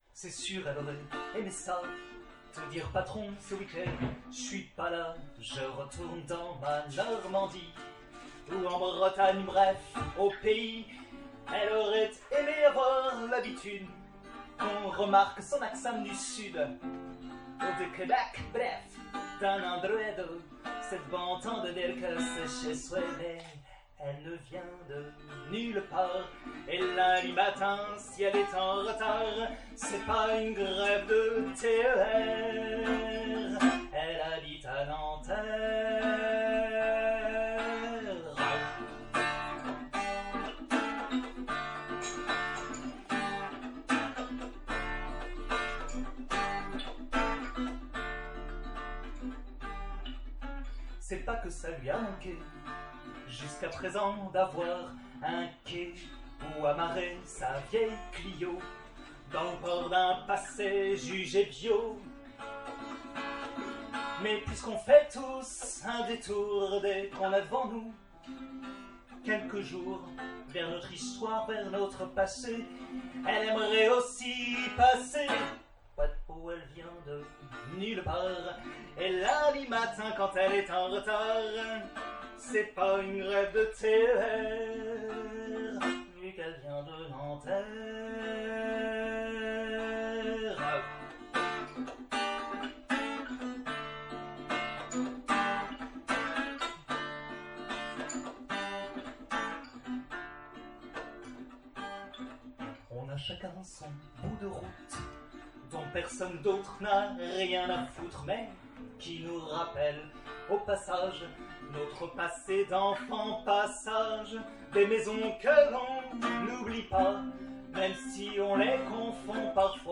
au Soum-soum (Paris 2)